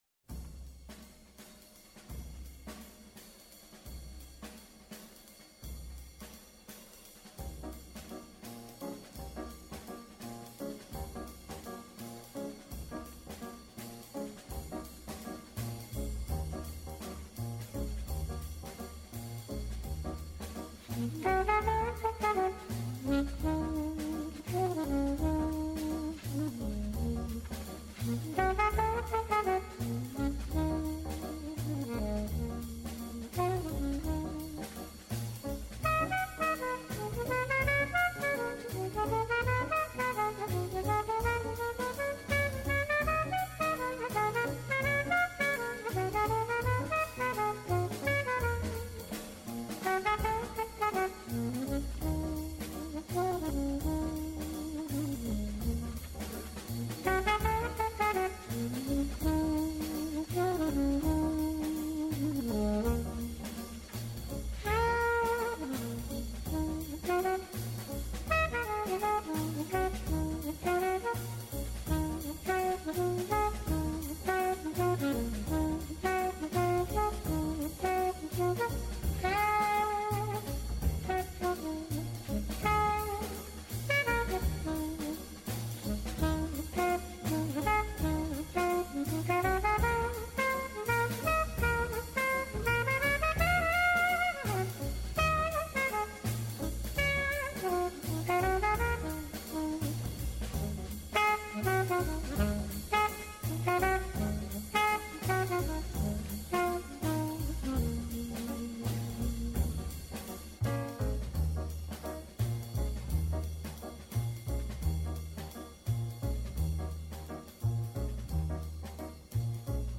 Καλεσμένος.
βρίσκεται στο μικρόφωνο του Πρώτου προγράμματος της Ελληνικής Ραδιοφωνίας στους 105,8 και 91,6. Αποχαιρετά την ημέρα που τελειώνει, ανιχνεύοντας αυτή που έρχεται. Διεθνή και εγχώρια επικαιρότητα, πολιτισμός, πρόσωπα, ιστορίες αλλά και αποτύπωση της ατζέντας της επόμενης ημέρας συνθέτουν ένα διαφορετικό είδος μαγκαζίνου με στόχο να εντοπίσουμε το θέμα της επόμενης ημέρας.